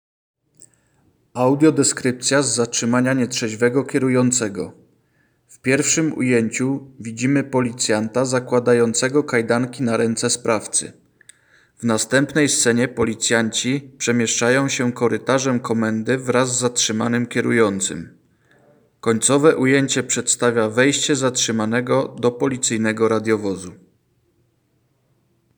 Nagranie audio Audiodeskrypcja_z_zatrzymania_nietrzezwego.m4a